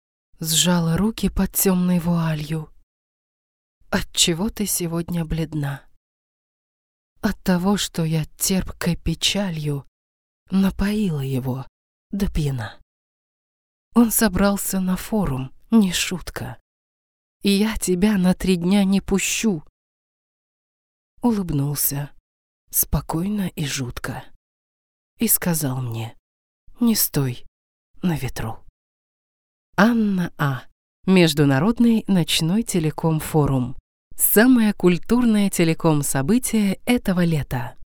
Жен, Рекламный ролик/Зрелый
Микрофон: RODE NT2-A, звуковая карта: Focusrite Scarlett 2Pre USB, профессиональная студия.